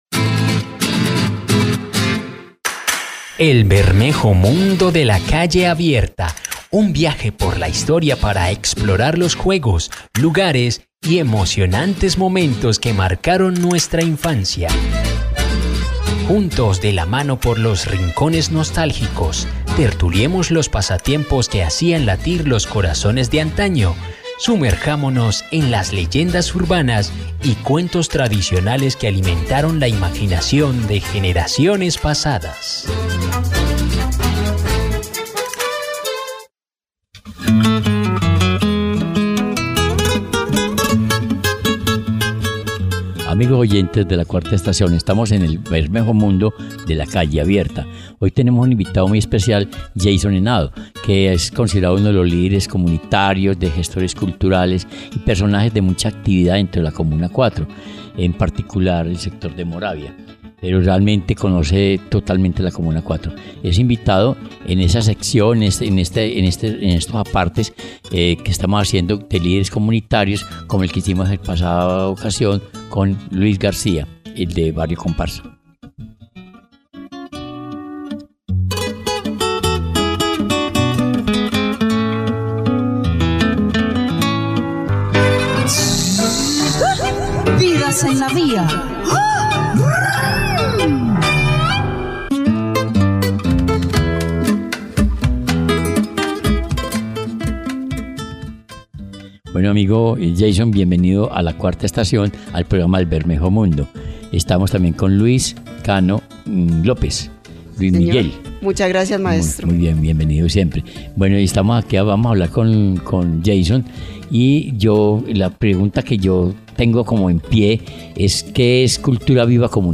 programa radial